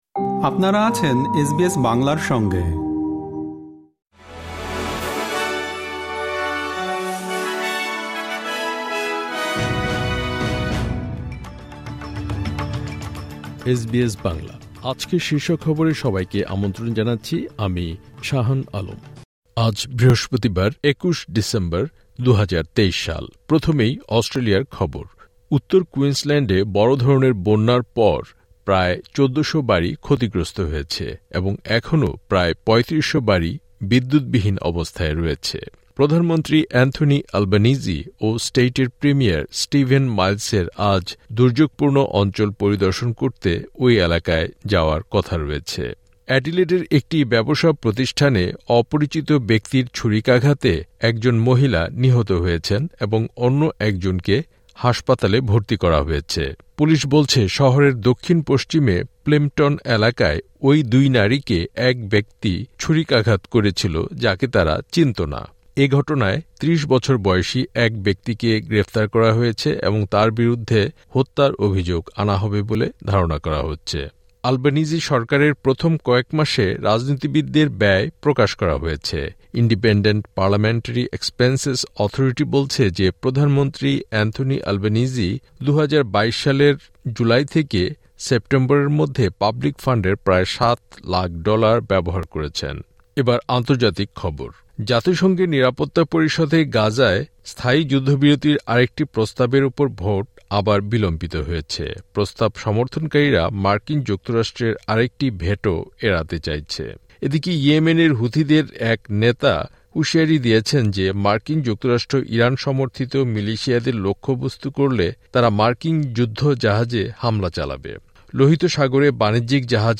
এসবিএস বাংলা শীর্ষ খবর: ২১ ডিসেম্বর, ২০২৩